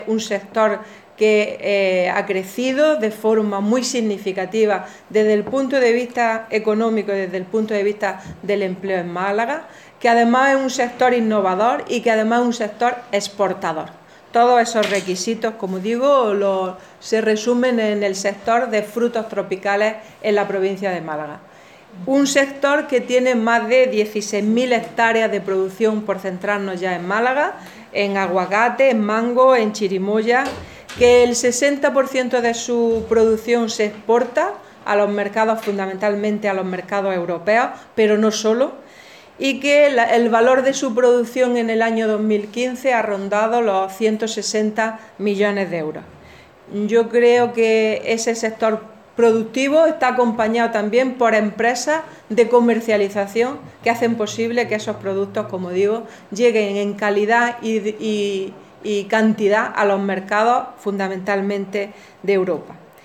Declaraciones Carmen Ortiz sobre el sector